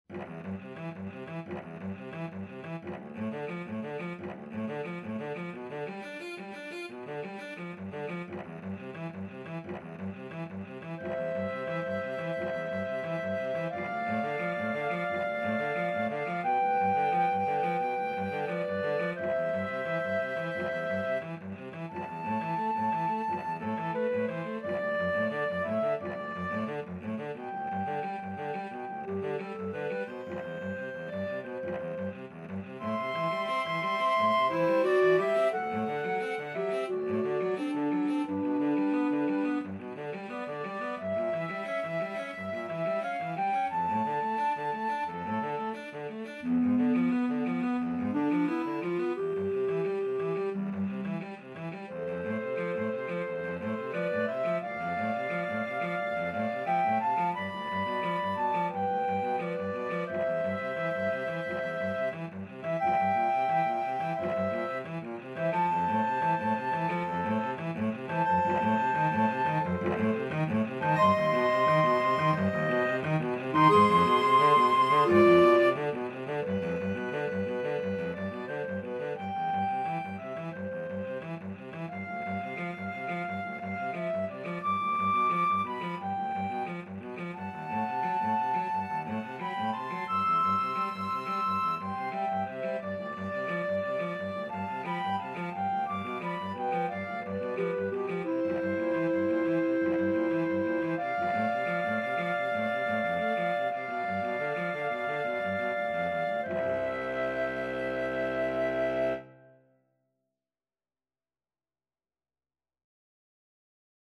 Free Sheet music for Flexible Mixed Ensemble - 3 Players
Flute
Clarinet
Cello
C major (Sounding Pitch) (View more C major Music for Flexible Mixed Ensemble - 3 Players )
Andante =c.88
4/4 (View more 4/4 Music)
Classical (View more Classical Flexible Mixed Ensemble - 3 Players Music)